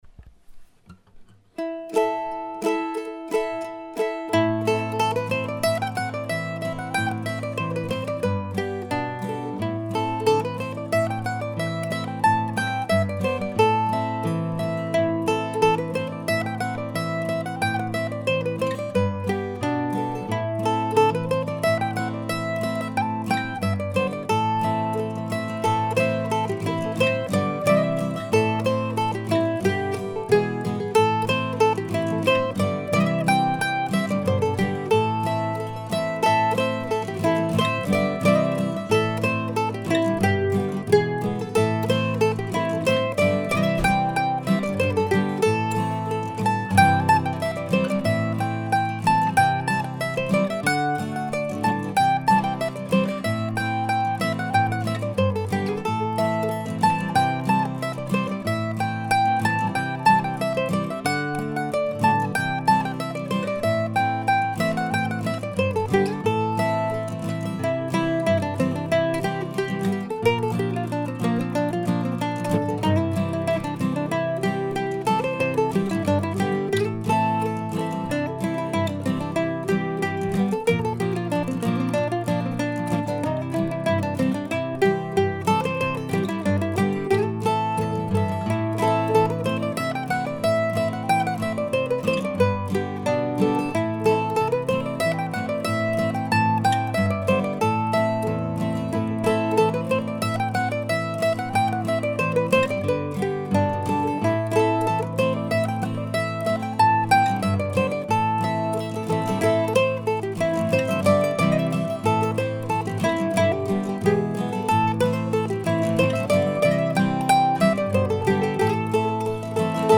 I came across a reference to St. Anthony and his sermon to the fishes in Alberto Manguel's wonderful book The Library at Night (p.216) during the time that this four part tune was looking for a title.